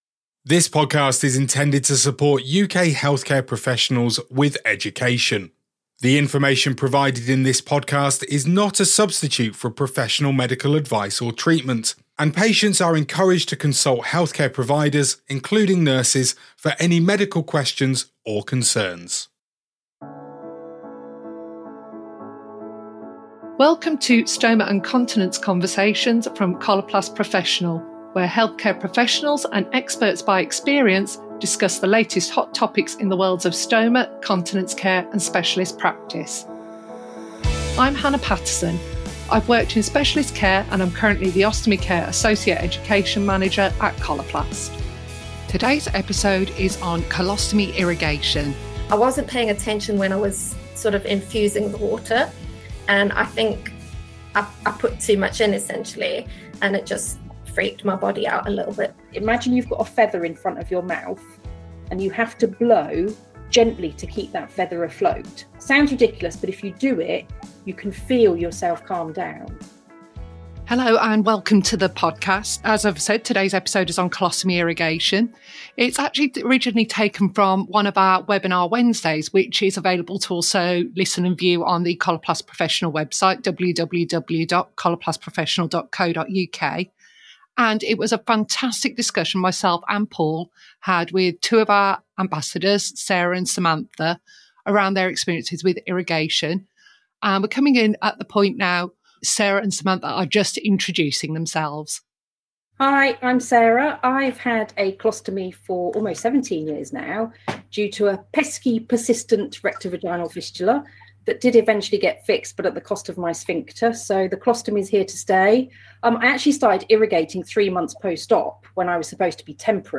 In this episode, taken from a Coloplast Professional Webinar